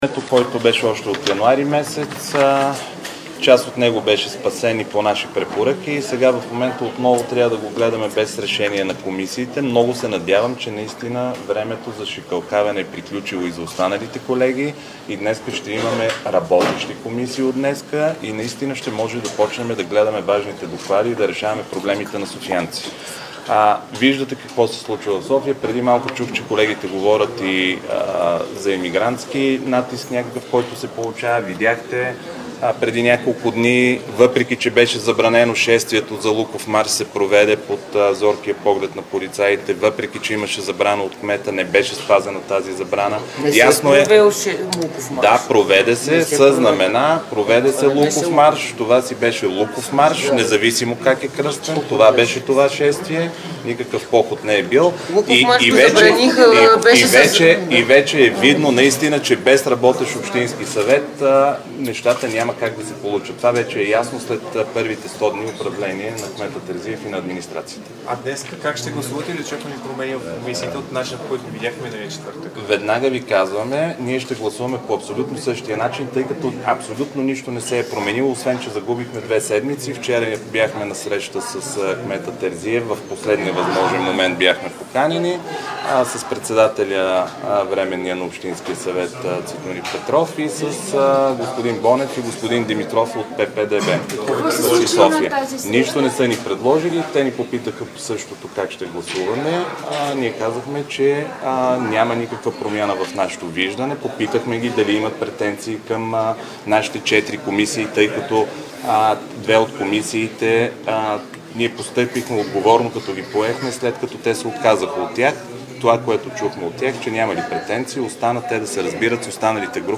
11.12 - Заседание на Министерски съвет.
- директно от мястото на събитието (Министерски съвет)